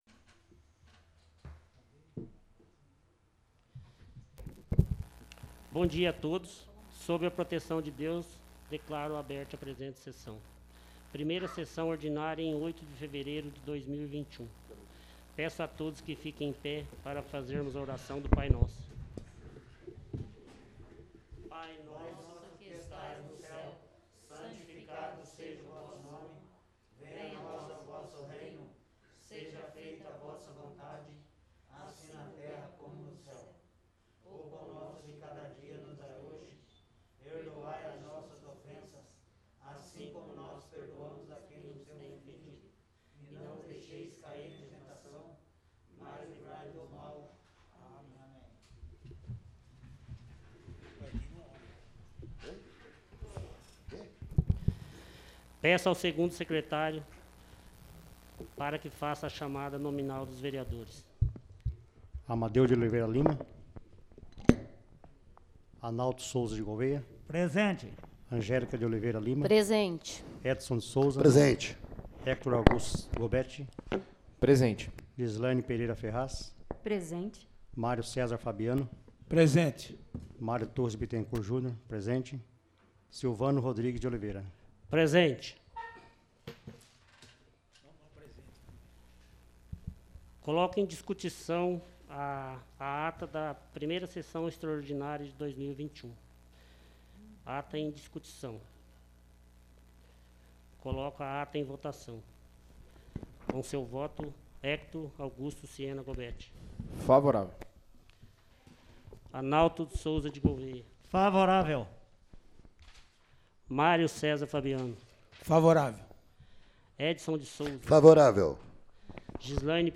1ª Sessão Ordinária